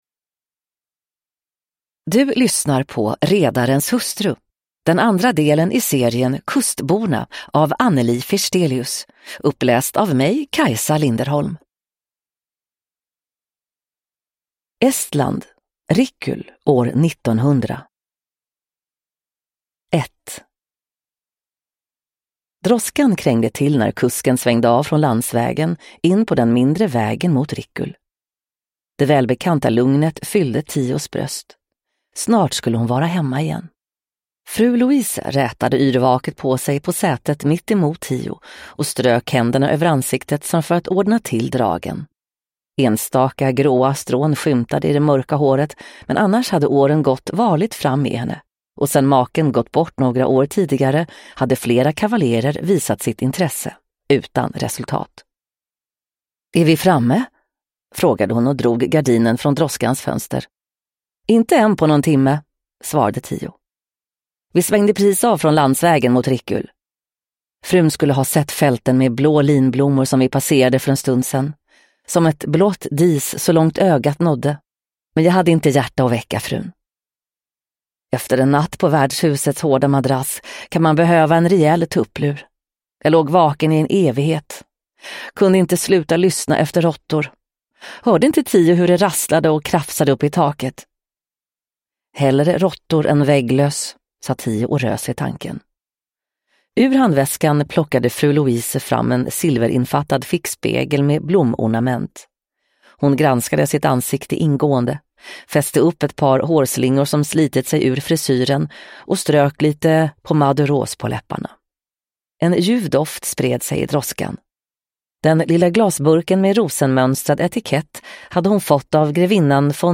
Redarens hustru – Ljudbok